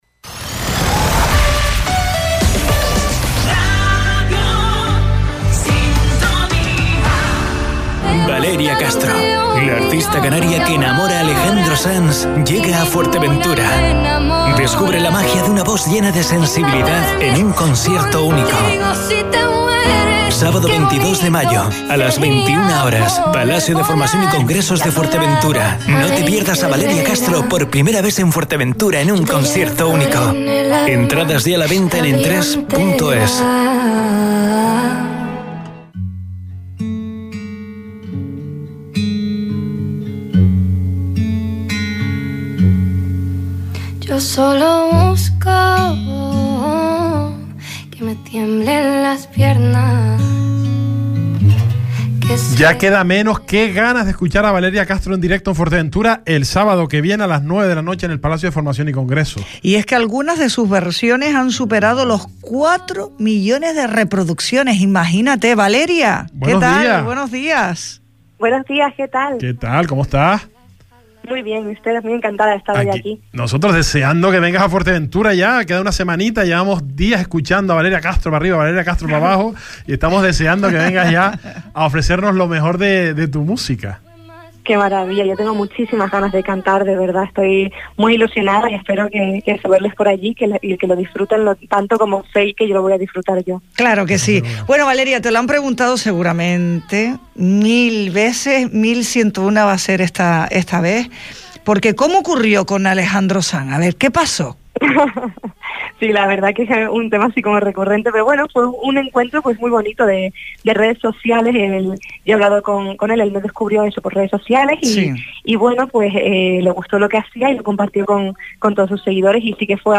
Valeria Castro, la joven cantante a la que Alejandro Sanz descubrió durante la cuarentena en redes sociales, participó hoy en ‘El salpicón’ donde se mostró ilusionada con su próxima actuación en el Palacio de Formación y Congresos de Ftva.